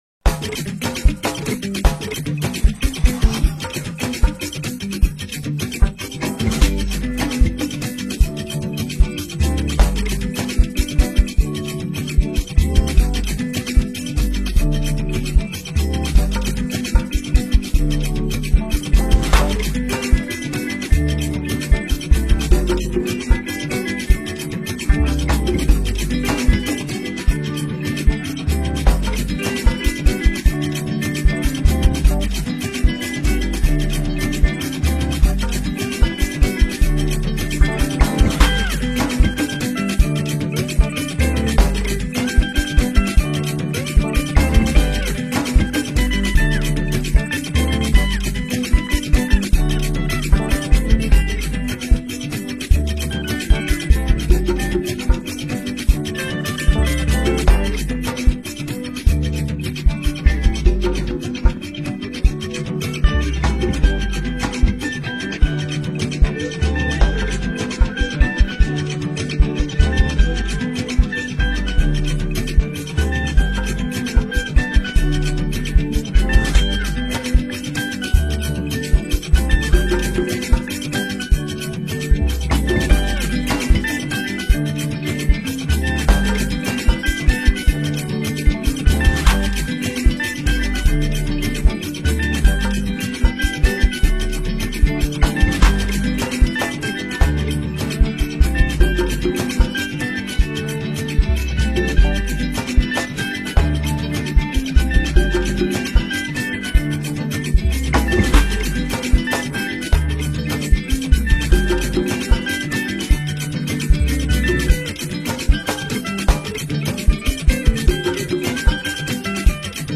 The new hit instrumental is available for free download.